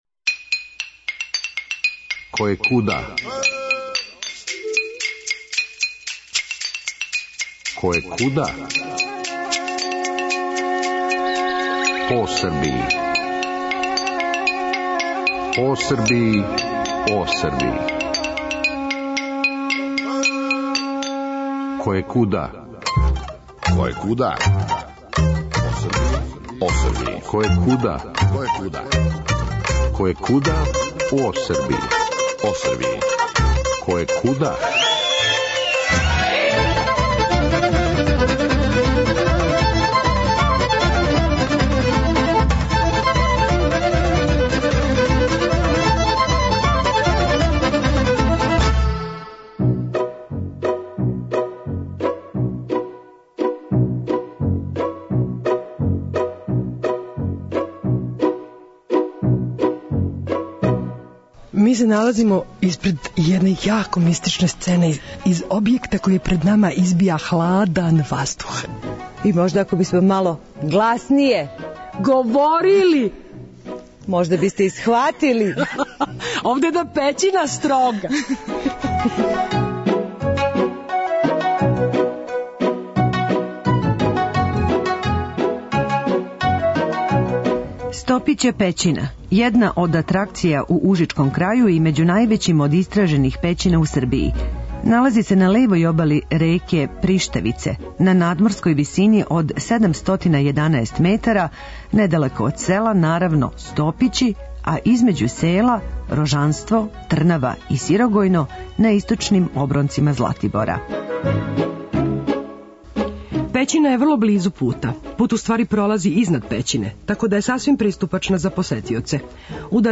Којекуда ове недеље у Стопића пећини на Златибору. Ова пећина је једна од атракција у ужичком крају и међу највећим од истражених пећина у Србији.